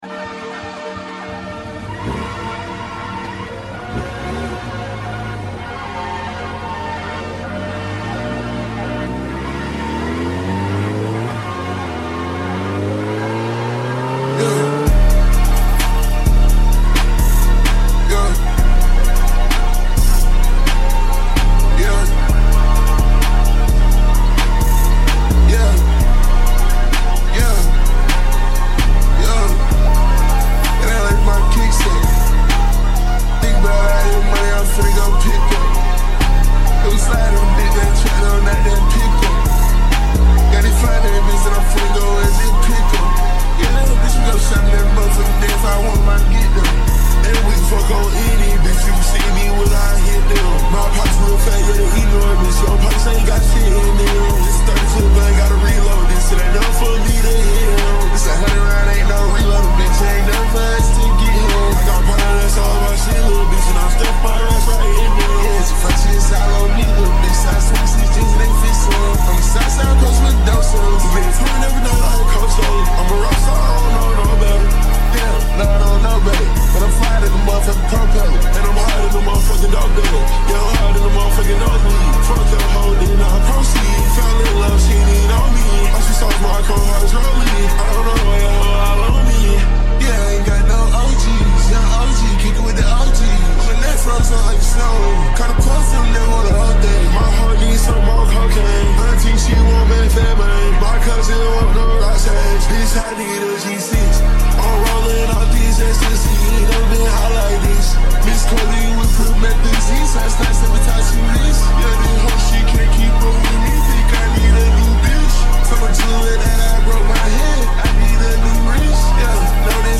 slowed/reverb